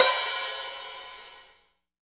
HAT REAL H05.wav